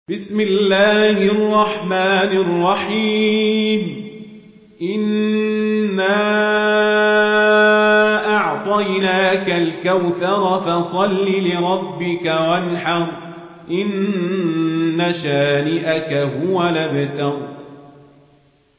Murattal Warsh An Nafi